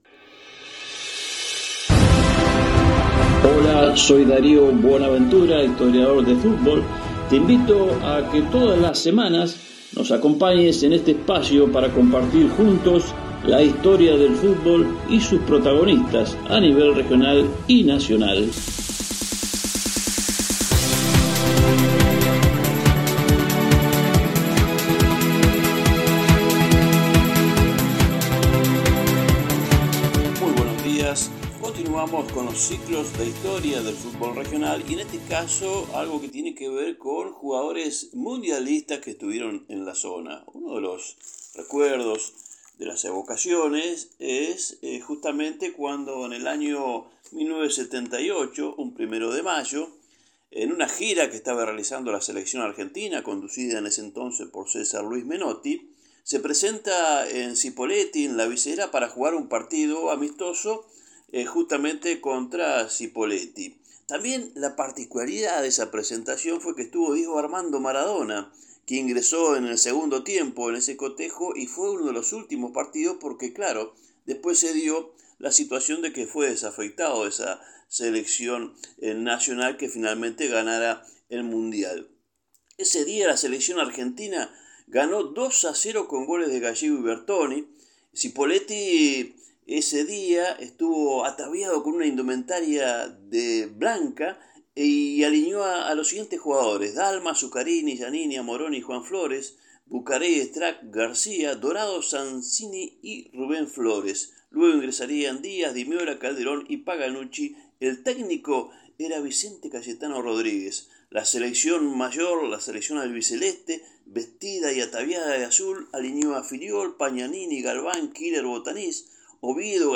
Escuchá la historia narrada